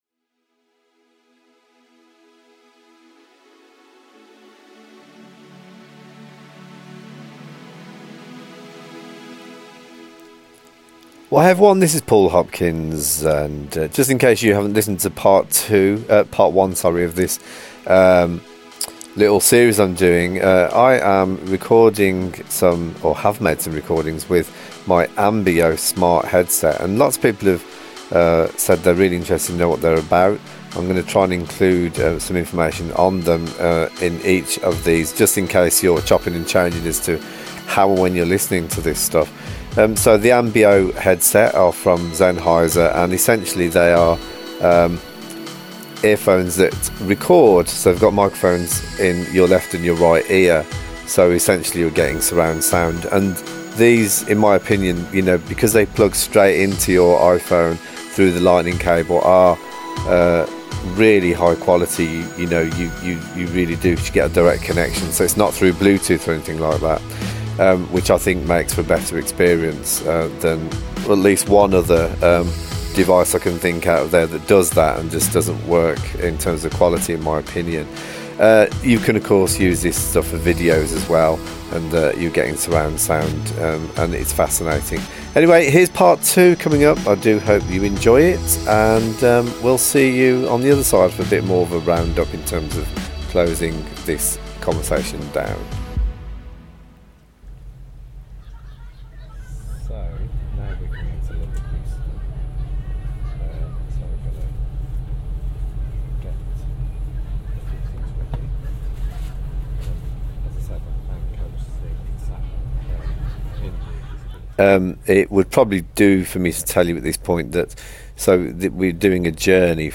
Amazing Ambeo Part 2 Arrival at London Euston; Going underground and sounds of the tube
Join us as we play with the Ambeo Smart Headphones from Zennheiser. We arrive in London Euston and walk the platform, traverse Euston main concourse and head onto the tube.